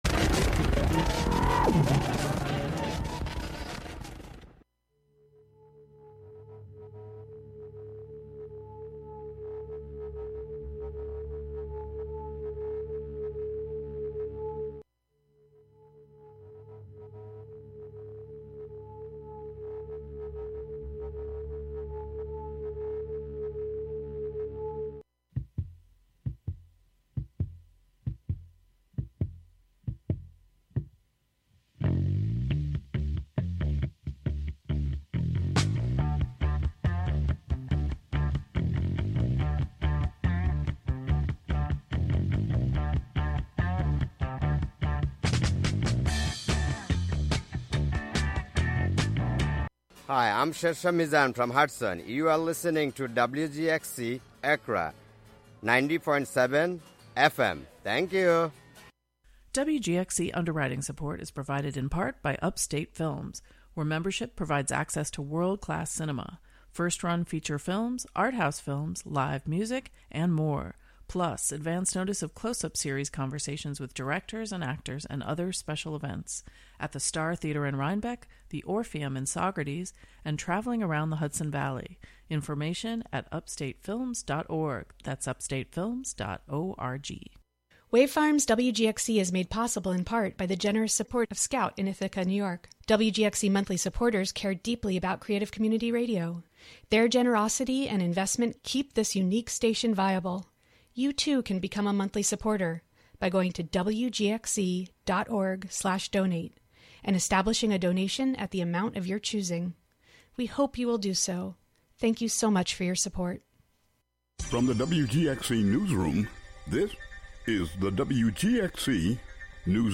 The "WGXC Morning Show" features local news, interviews with community leaders and personalities, a rundown of local and regional events, weather updates, and more about and for the community. The show is a place for a community conversation about issues, with music, and more.